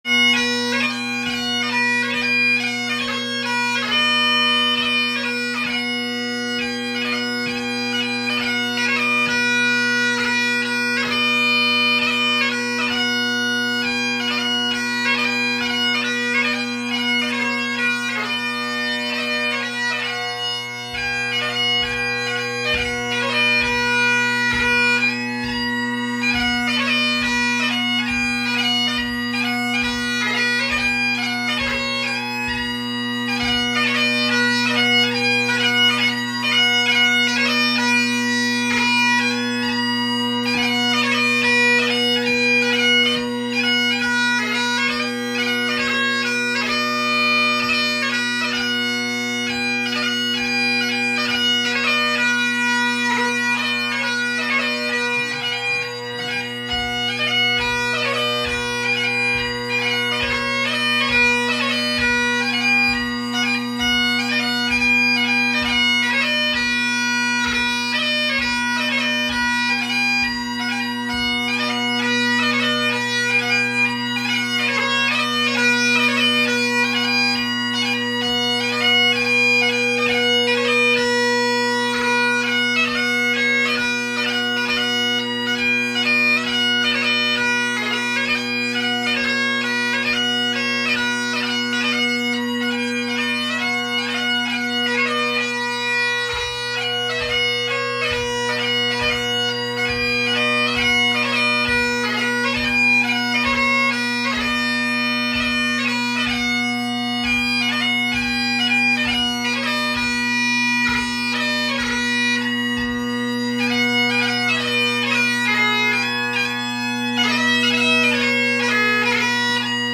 Liddell in London
To bring everyone back to reality, here’s a recording of Stuart Liddell’s winning double MSR from the recent London competition. Stuart’s tunes, chosen from the six of each entered, are John MacColl’s March to Kilbowie Cottage, Bonnie Ann, John Roy Stewart, Bob o’ Fettercairn, Cockerel in the Creel, and the Little Cascade:
All tunes are very well played of course, but listen to the measured performance of Bob o’ Fettercairn and the flow he gets in the reels. The perfect pipe and technique is a given.